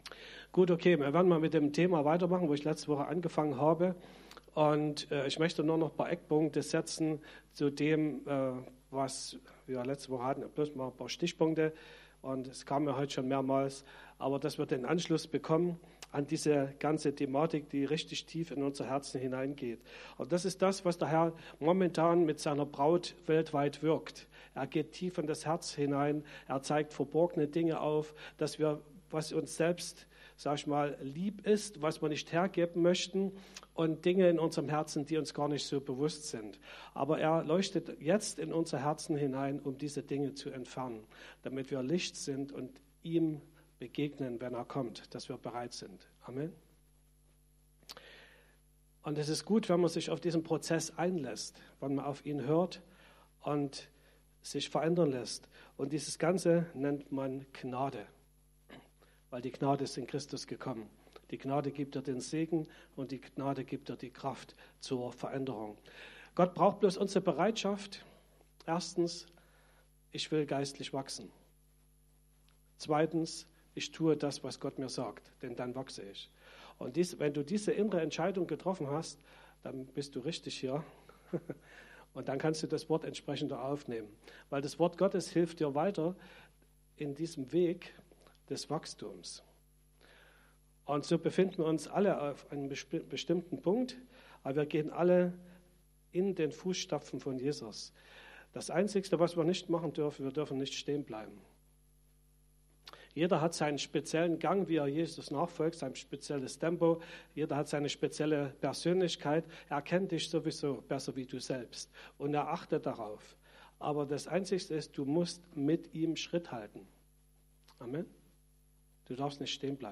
Predigten chronologisch sortiert